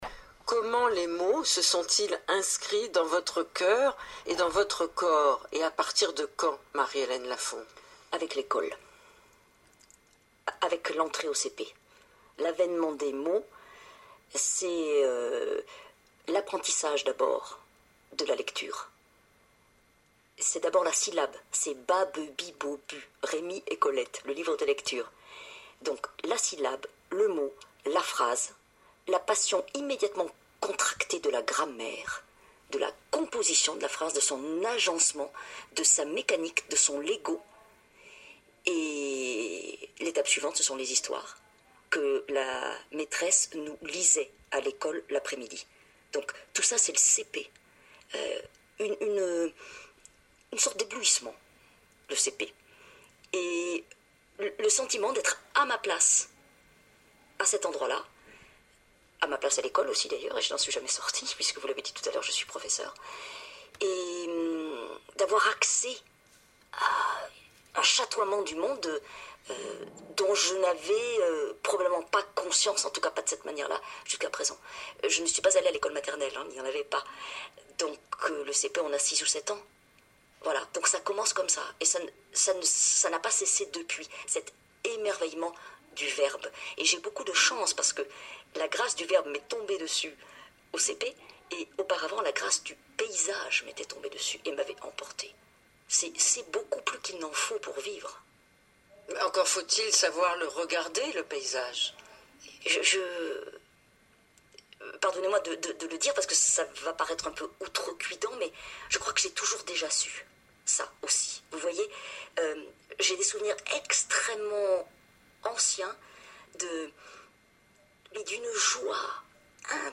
Il y était question de son enfance, de lecture, d’écriture, dans une langue où chaque mot est choisi. Je partage avec vous aujourd’hui la beauté de ses évocations, dans cette diction qui lui est si personnelle.